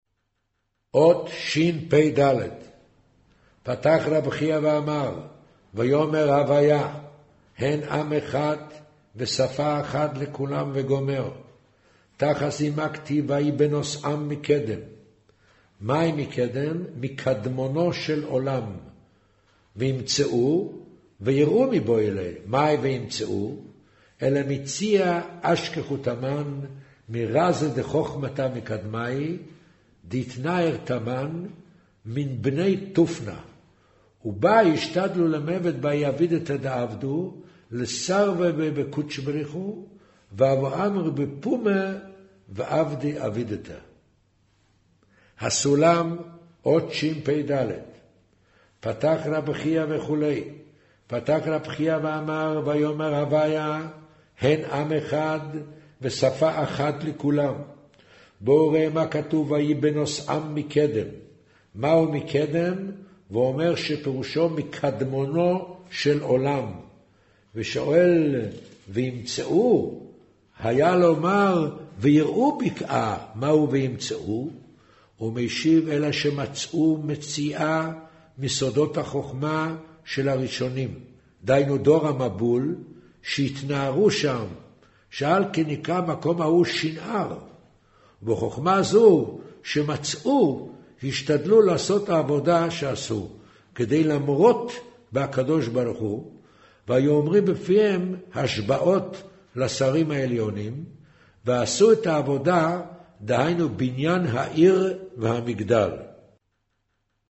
קריינות זהר, פרשת נח, מאמר ויאמר ה' הן עם אחד